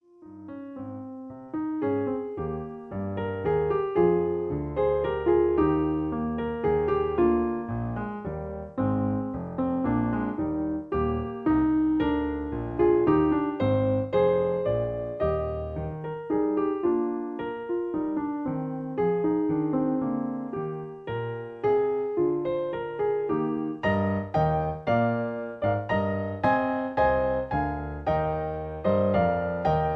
In A flat. Piano Accompaniment